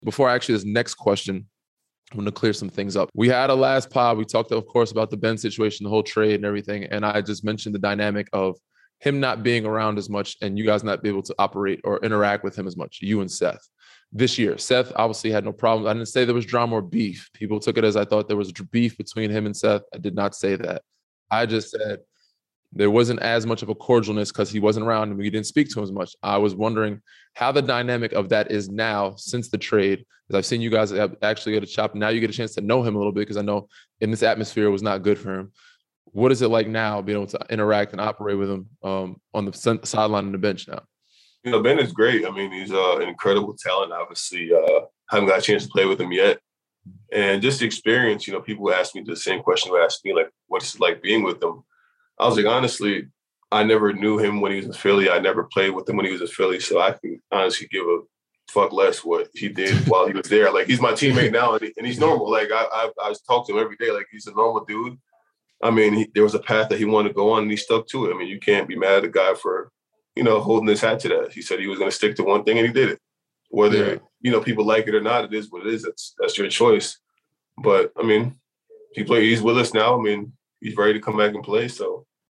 So on his most recent episode, Green had Drummond on as a guest, and there was clarification on this topic (audio courtesy of Inside the Green Room) –
Danny-addresses-Ben-comments-with-Drummond.mp3